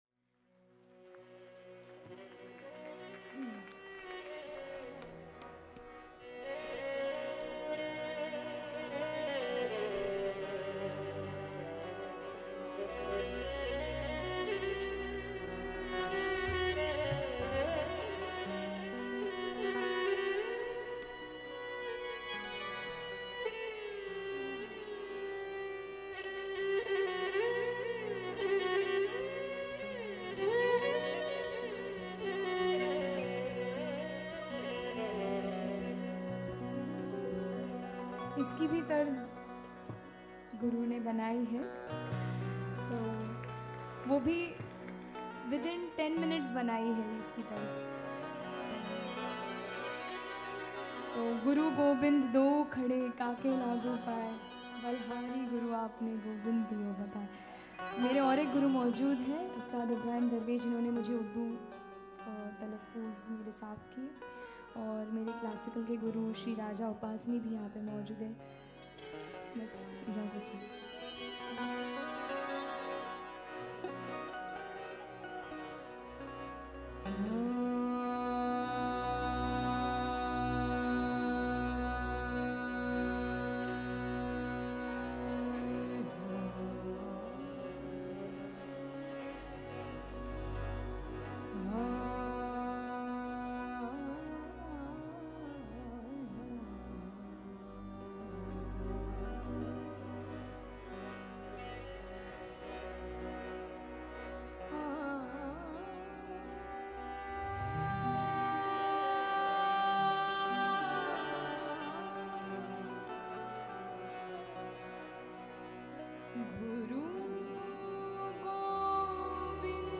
Qawwali, Punjab and Urdu